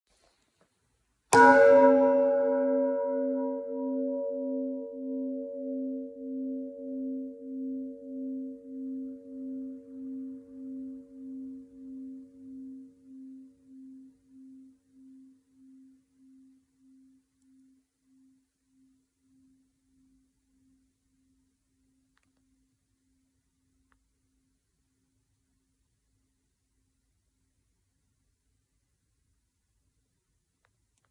Die verschiedenen Teiltöne der Glocken hört man an allen Anschlagspunkten, jedoch in jeweils unterschiedlicher Intensität.
Anschlagpunkt b [569 KB]
glocke-brauweiler-b.mp3